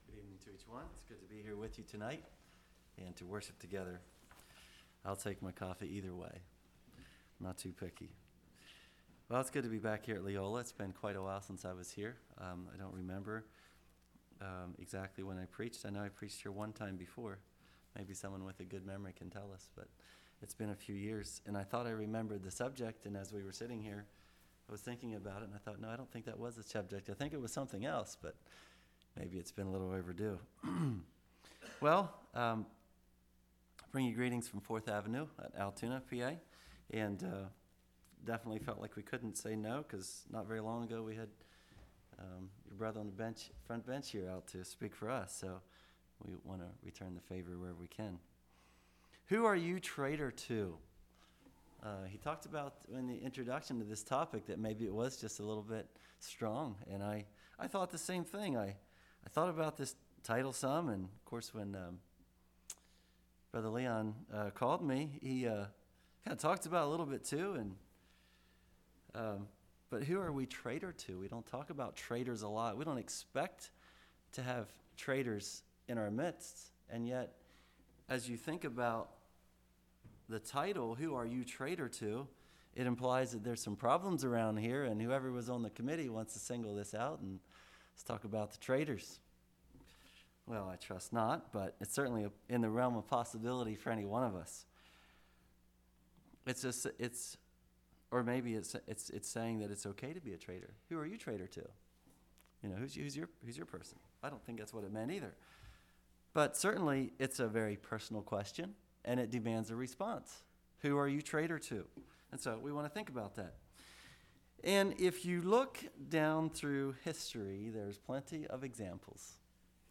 2022 Sermon ID